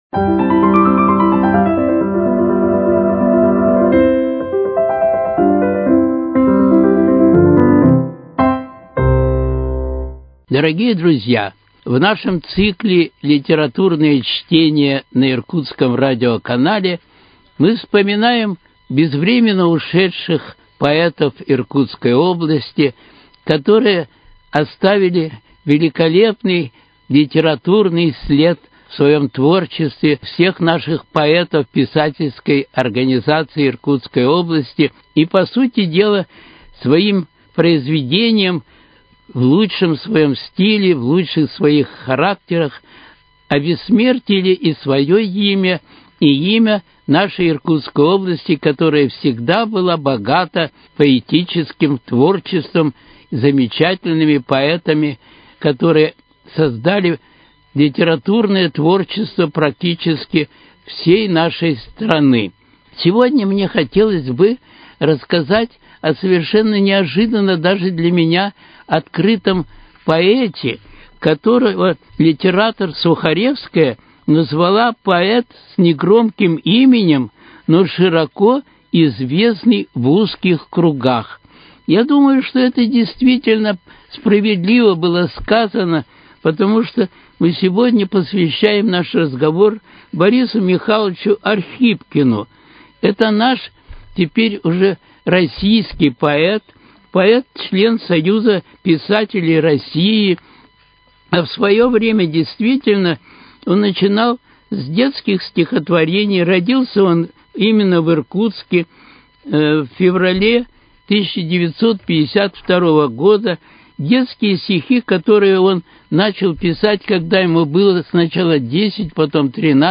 Литературные чтения: Стихи Бориса Архипкина